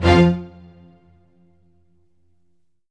ORCHHIT F3-R.wav